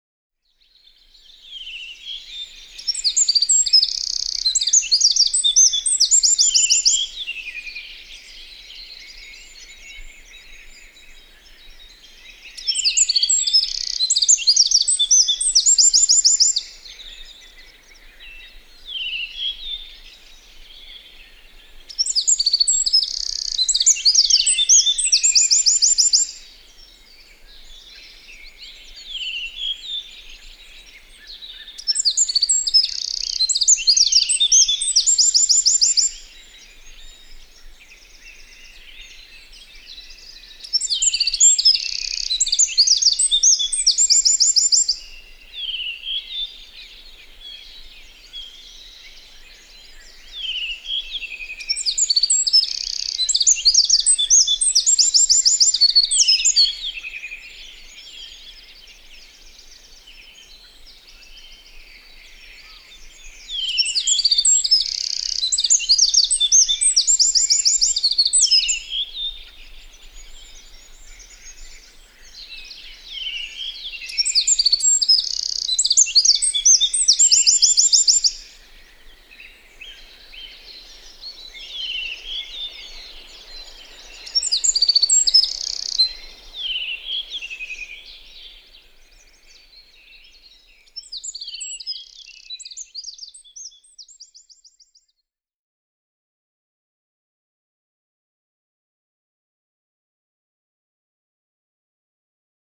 Käblik (Troglodytes troglodytes)
Laul on tämbri ja elementide poolest sarnane punarinna laulule (kõlavad viled, säravad trillerid, kiired sidinad), kuid vastupidiselt punarinnale väga rütmikas.
Selline see käblik on – väike, erakordselt valjuhäälne kuid pelglik laululind.